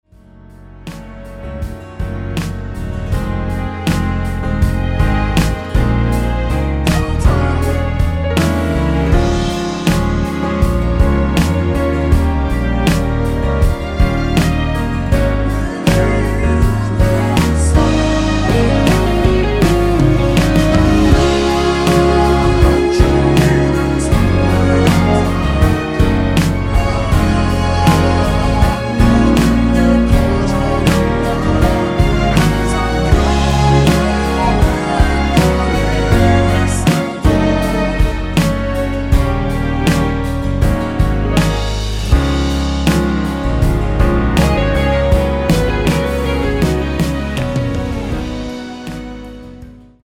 원키에서(-3) 내린 코러스 포함된 MR 입니다.(미리듣기 참조)
Db
앞부분30초, 뒷부분30초씩 편집해서 올려 드리고 있습니다.
중간에 음이 끈어지고 다시 나오는 이유는